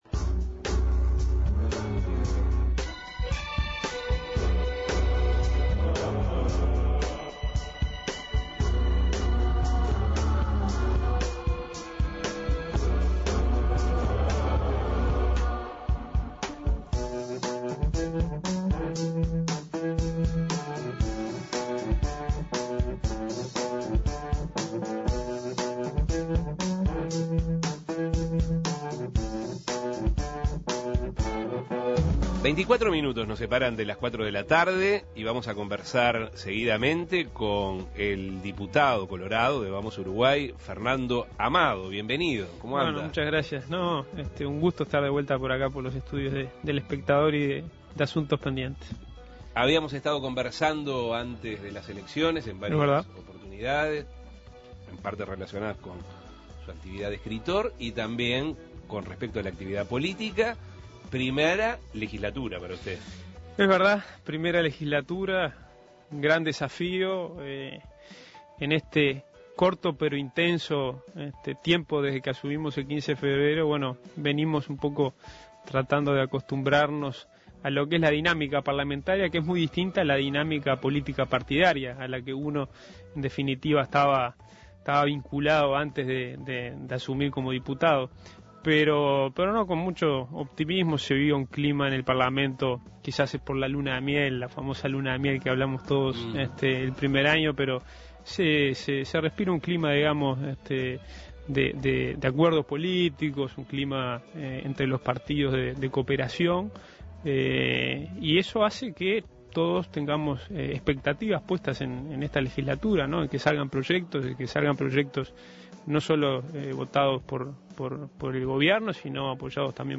El diputado debutante en esta legislatura del Partido Colorado, Fernando Amado, dialogó sobre si encontró similitudes con los parlamentarios de su generación, qué tiempo le está dedicando a esta actividad, su interés de crear una universidad paralela a la de la República, qué proyectos tiene su sector (Vamos Uruguay) en materia de seguridad pública, si considera que estaba para postularse para las Elecciones Municipales y cuál fue el criterio utilizado para la elección de los alcaldes que representarán a la agrupación, entre otras cosas. Escuche la entrevista.